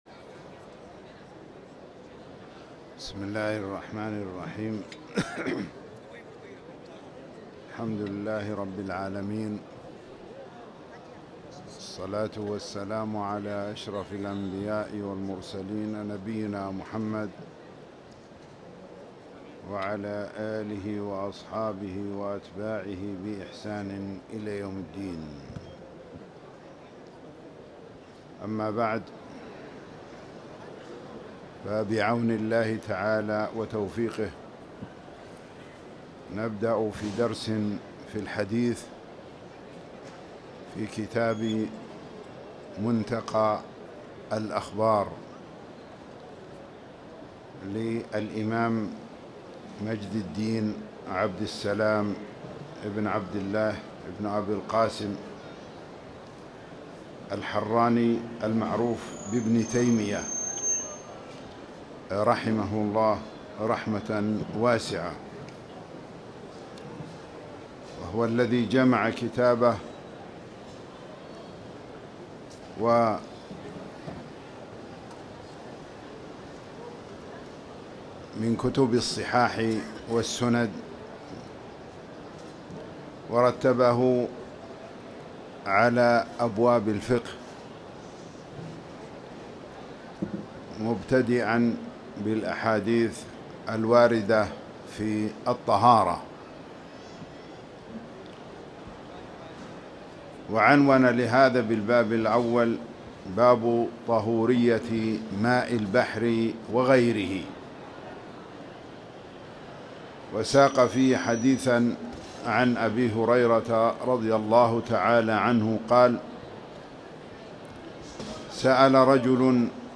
تاريخ النشر ١٣ شعبان ١٤٣٨ هـ المكان: المسجد الحرام الشيخ